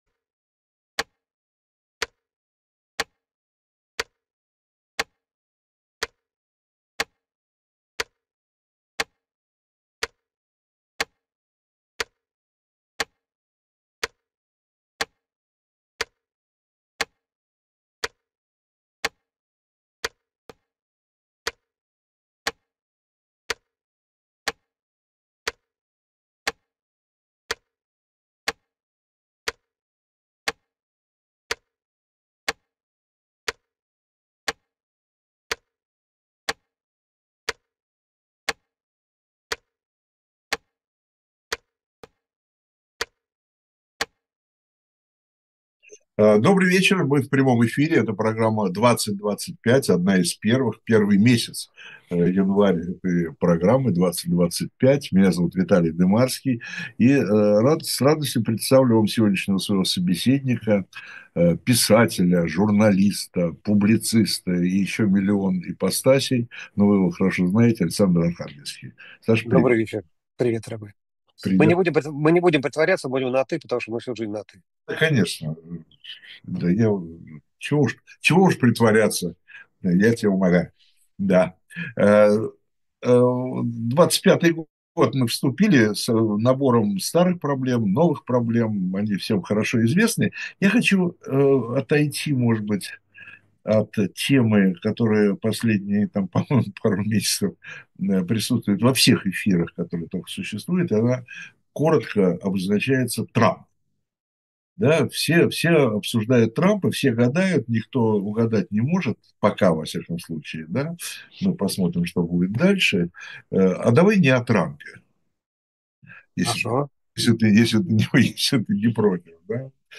Ведёт эфир Виталий Дымарский